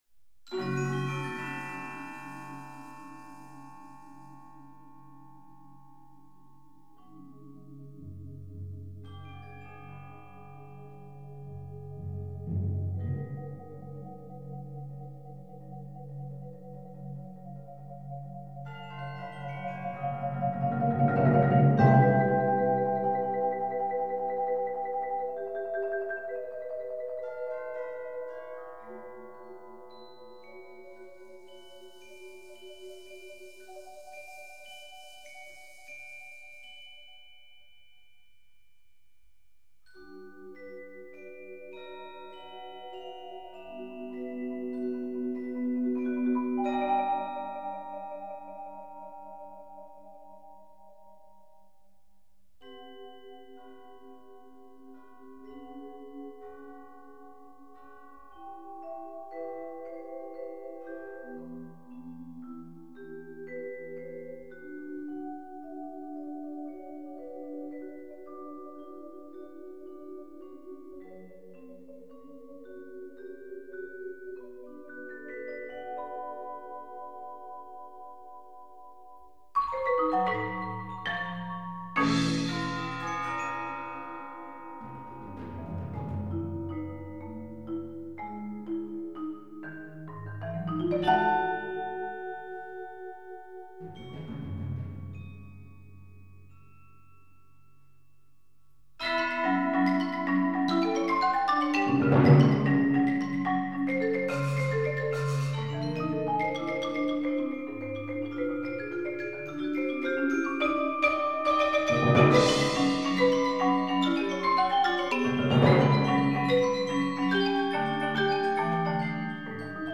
Bladmuziek voor flexibel ensemble.